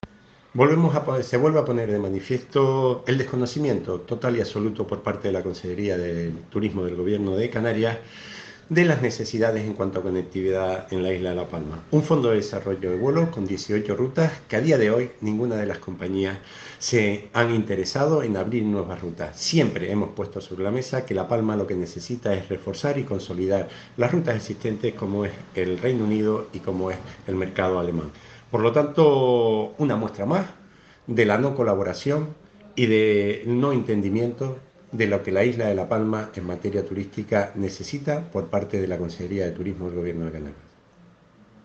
Declaraciones audio Raúl Camacho FDT.mp3